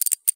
🌲 / midnight_guns mguns mgpak0.pk3dir sound weapon magnum
rachet.ogg